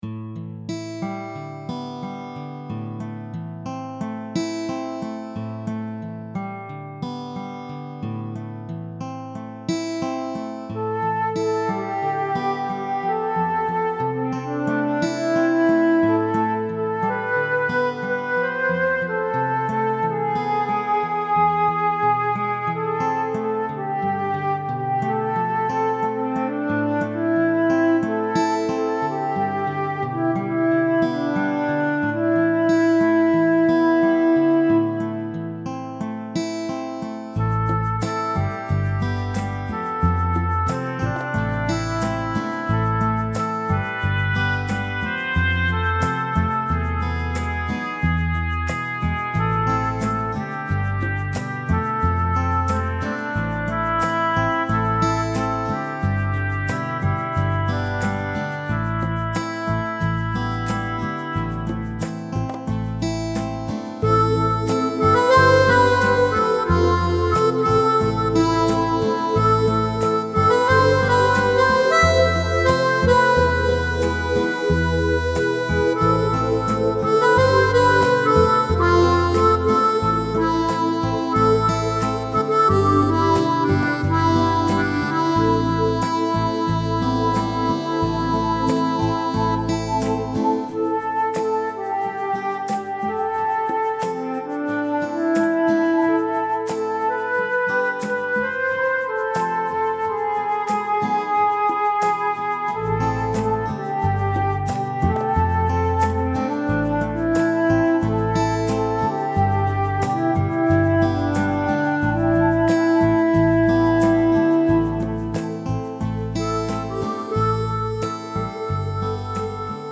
lyrics     mp3 backing w. melody   score